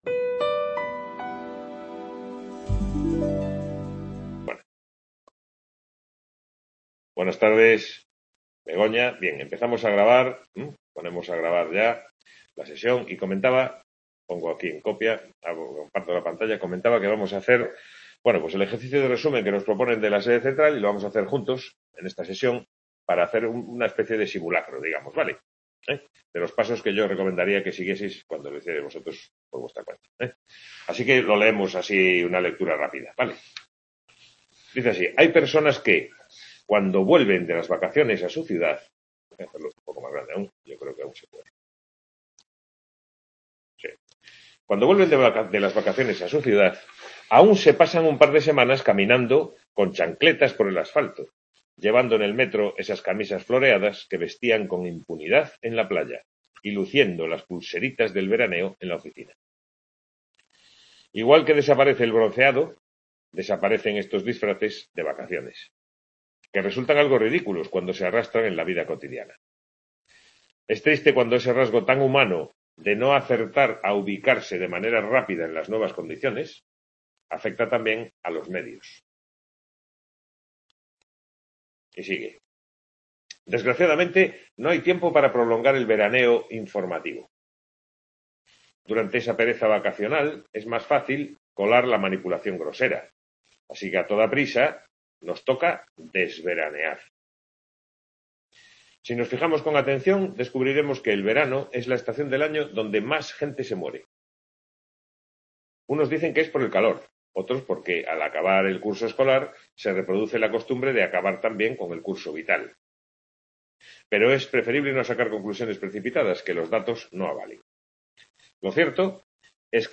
Sesión de turoría (03/11/2021) Description Sesión de tutoría de la asignatura Comentario de textos, dedicada a la técnica del resumen.